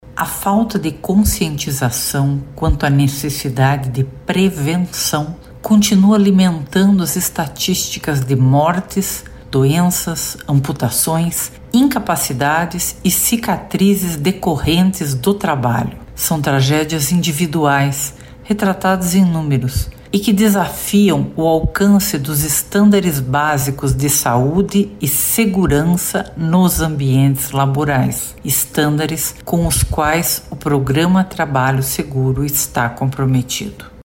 A juíza da 12ª Vara do Trabalho de Curitiba e gestora regional do Programa Trabalho Seguro no Paraná, Sandra Mara Flügel Assad, falou sobre esses dados.